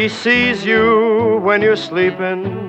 Harmonisator
fra en amerikansk sang som er bearbeidet med harmonisatoren: Lyd.